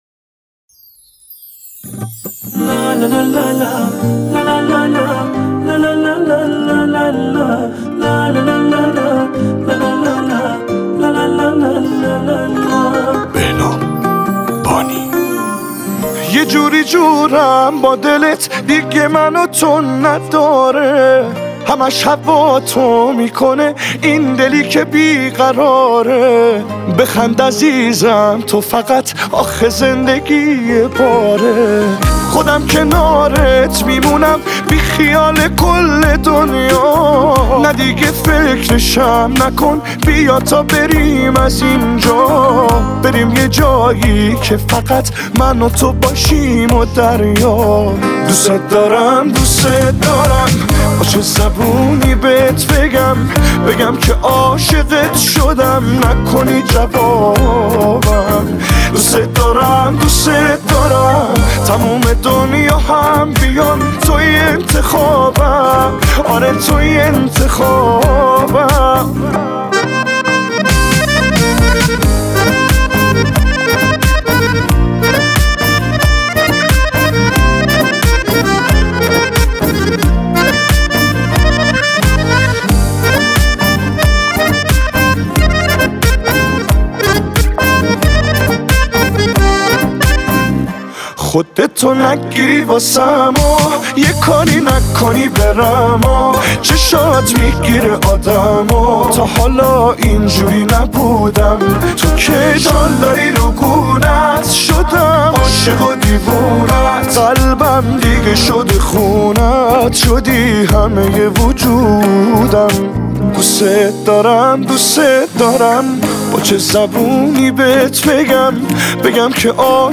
Эрон мусиқаси